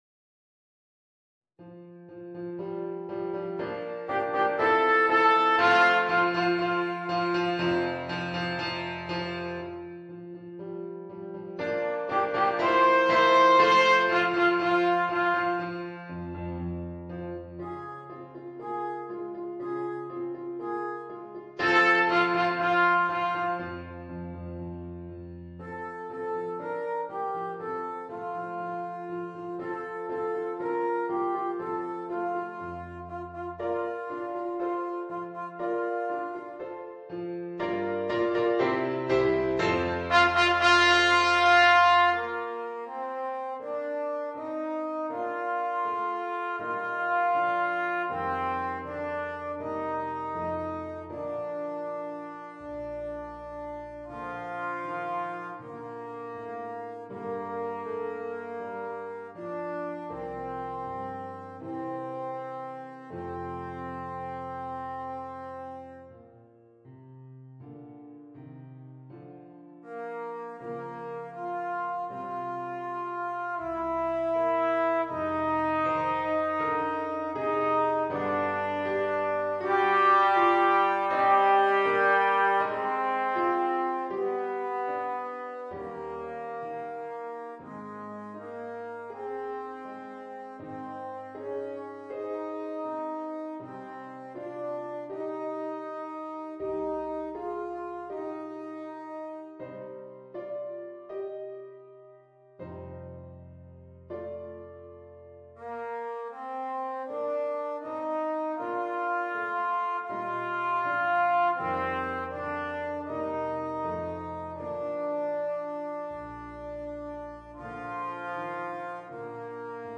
Voicing: Cornet and Piano